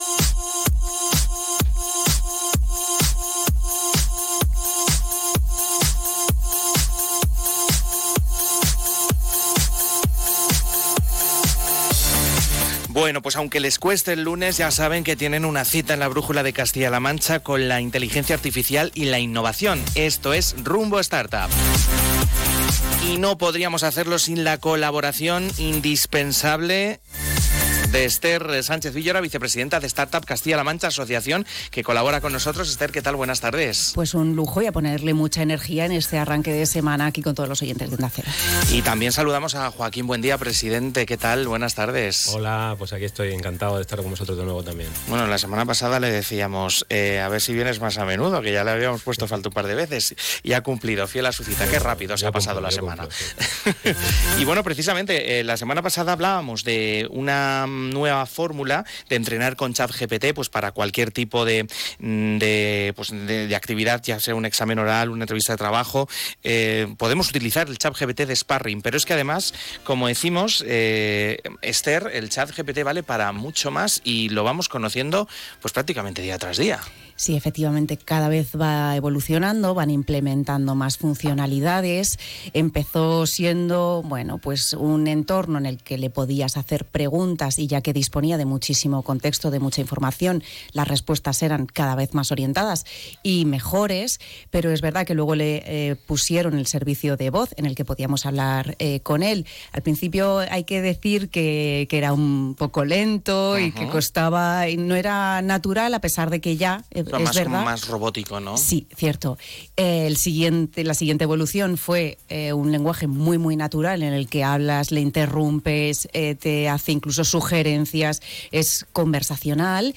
Durante el programa, se desafió a ChatGPT a recorrer el estudio de Onda Cero con su mirada digital, describiendo con precisión desde los decorados hasta los simpáticos locutores detrás del micrófono.